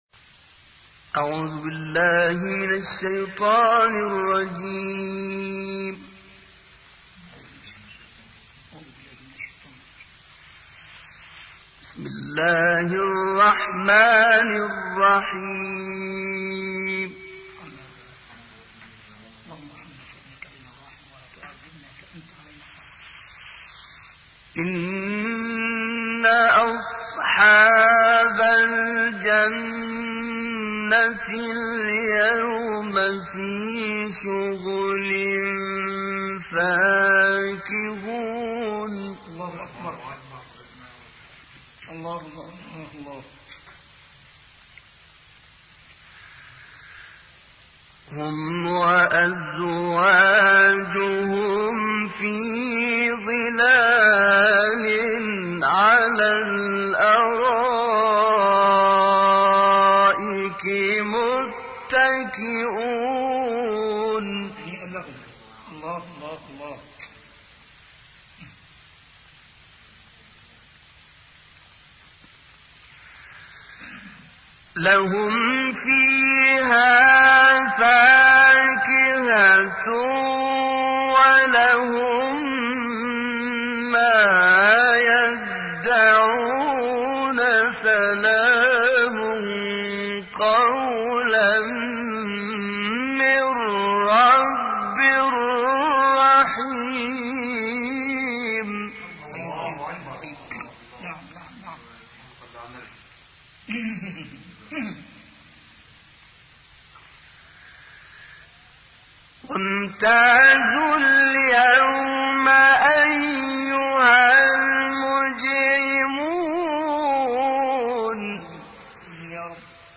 تلاوتی از دوران جوانی «طنطاوی»
گروه فعالیت‌های قرآنی: تلاوت آیاتی از سوره‌های یس و حاقه که در دوران جوانی عبدالوهاب طنطاوی اجرا شده است، ارائه می‌شود.